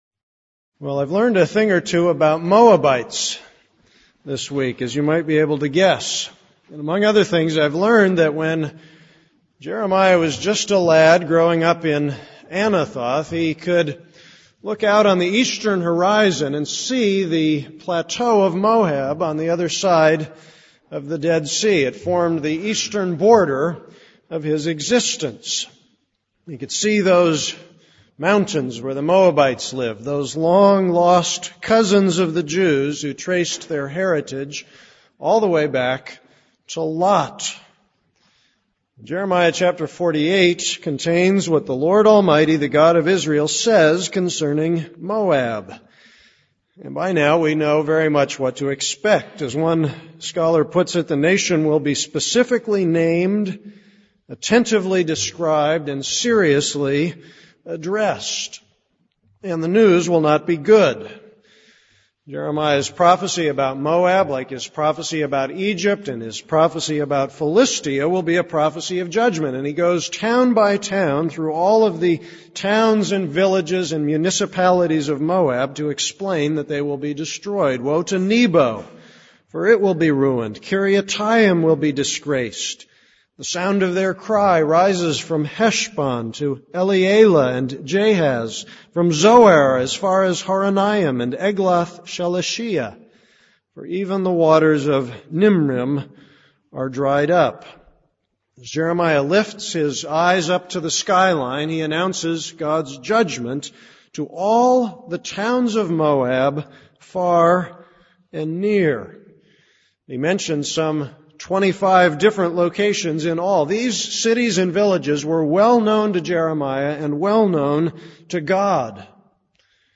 This is a sermon on Jeremiah 48:1-4.